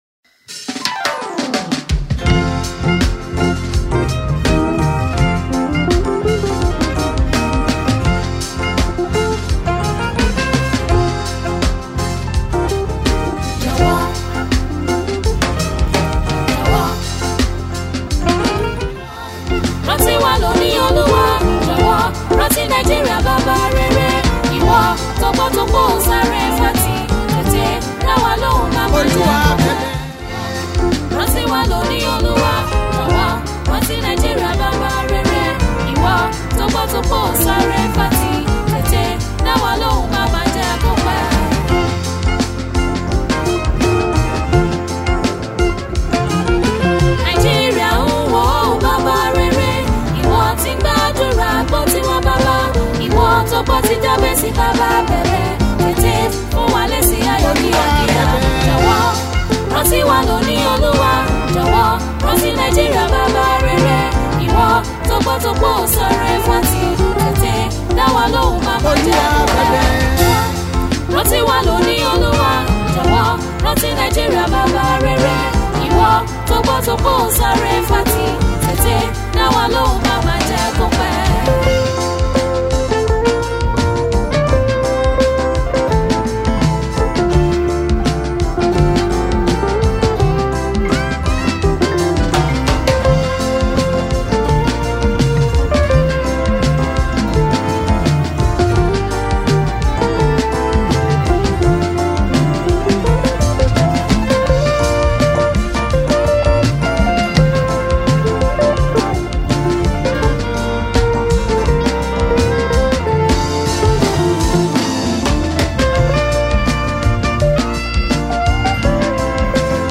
Yoruba Gospel Music